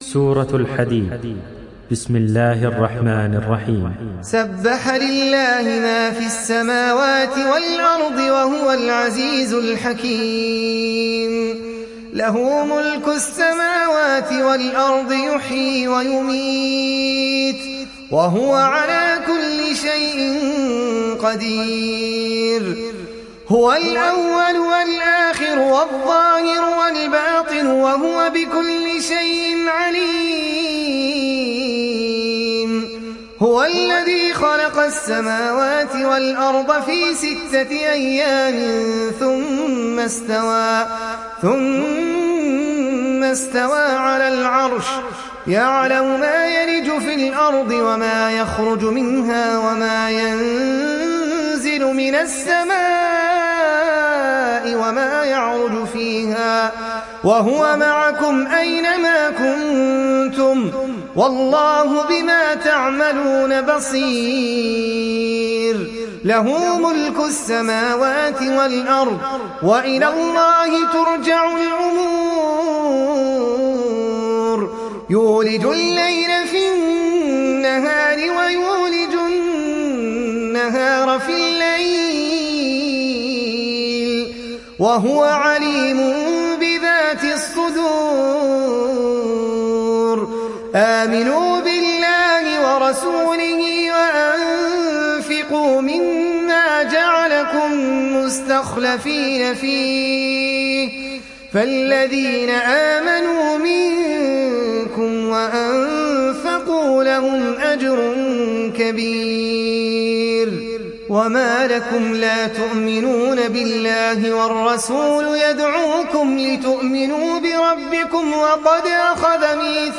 تحميل سورة الحديد mp3 بصوت أحمد العجمي برواية حفص عن عاصم, تحميل استماع القرآن الكريم على الجوال mp3 كاملا بروابط مباشرة وسريعة